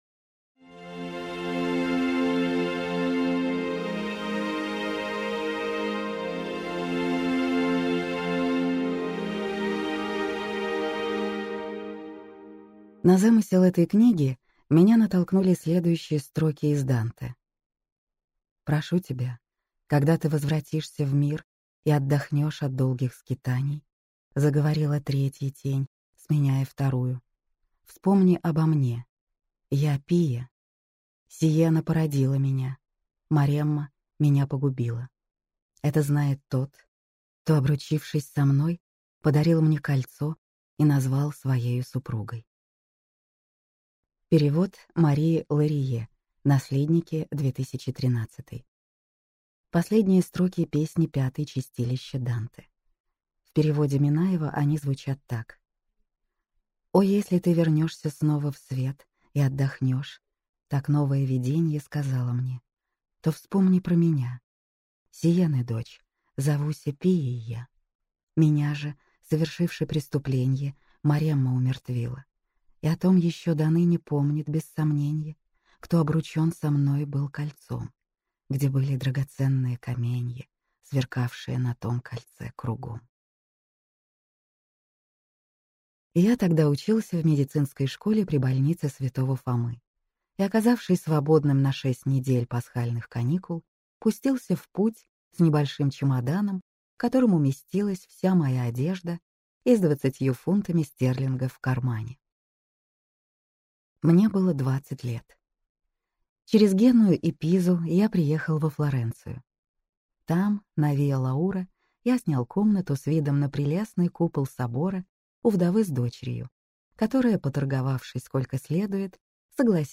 Аудиокнига Узорный покров - купить, скачать и слушать онлайн | КнигоПоиск